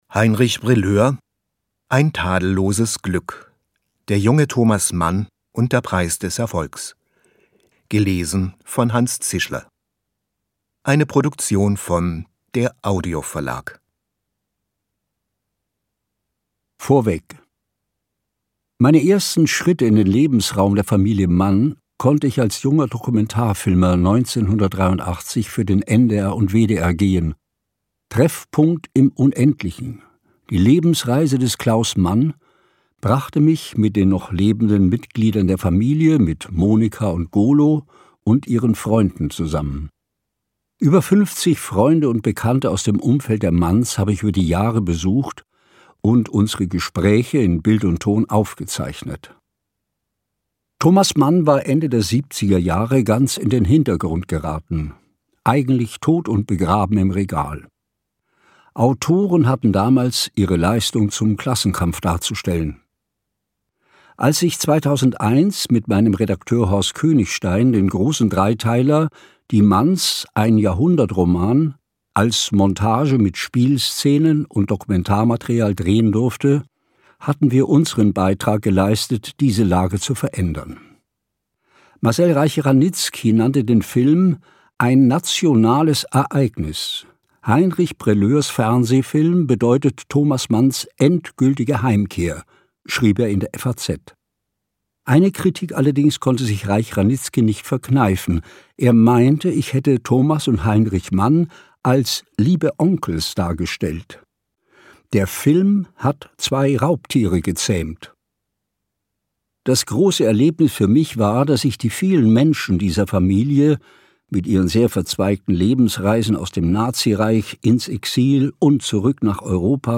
Ungekürzte Lesung mit Hanns Zischler (2 mp3-CDs)
Hanns Zischler (Sprecher)